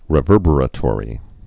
(rĭ-vûrbər-ə-tôrē)